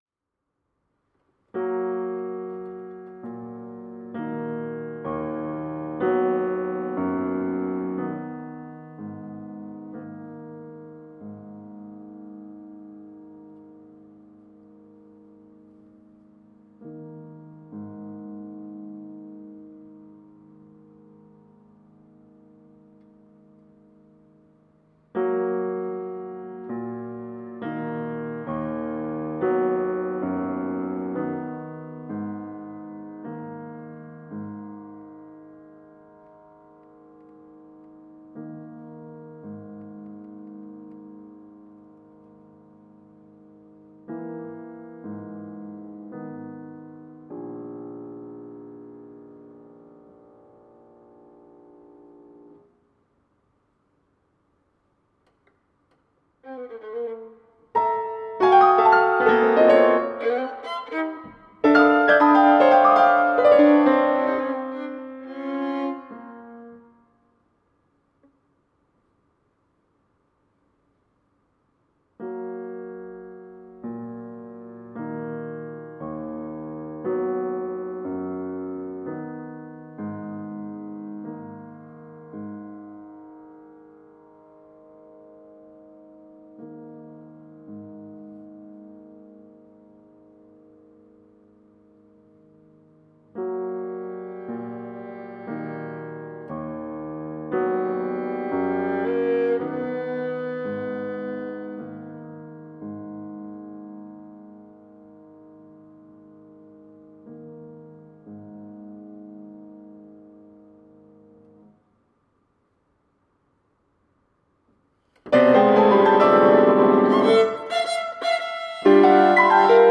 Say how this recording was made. LOFI Rehearsal recordings.